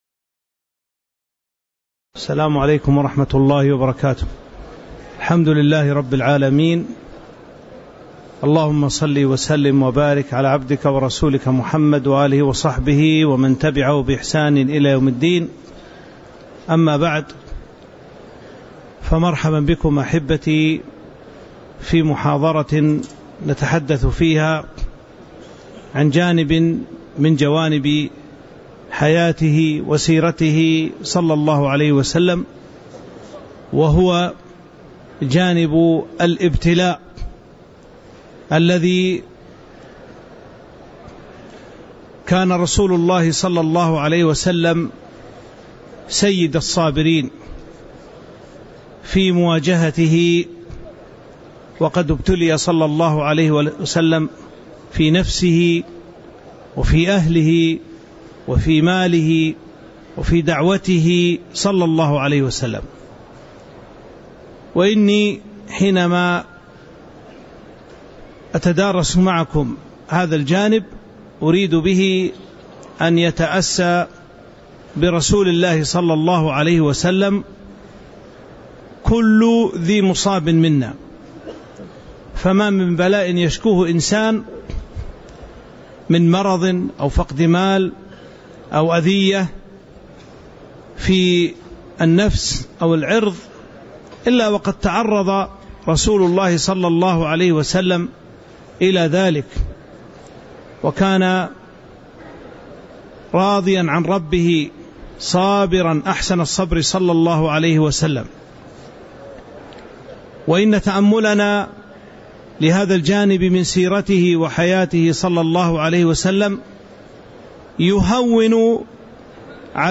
تاريخ النشر ٢٣ ذو الحجة ١٤٤٥ هـ المكان: المسجد النبوي الشيخ